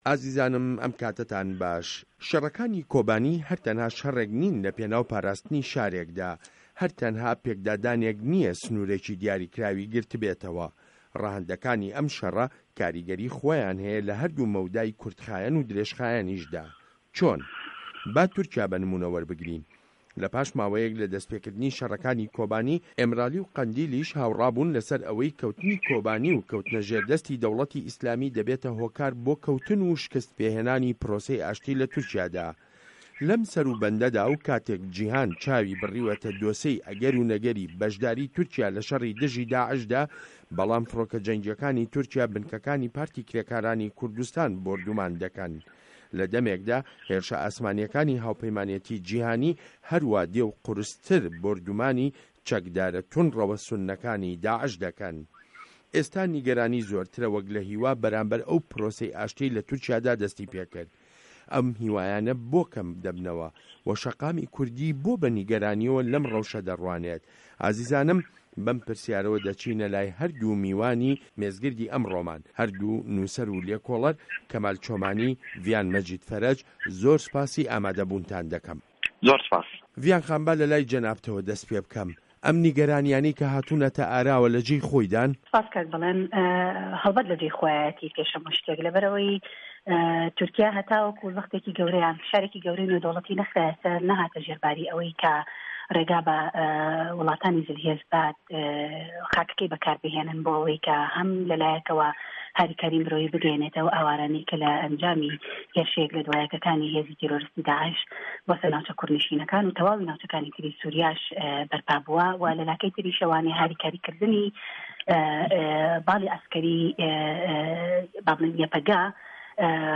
مێزگرد : کۆبانی و ئاشتی له‌ تورکیا